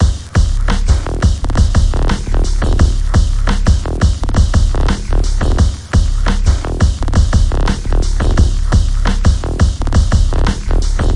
其他 " Sprk drums loop 86bpm
Tag: 桶-loop 86bpm Ableton公司